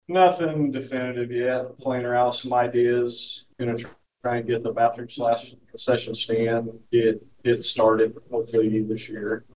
Riley County Commissioners held their Monday meeting at the Ogden Community Center, as part of a quarterly effort by the commission to take their meetings to constituents outside of Manhattan.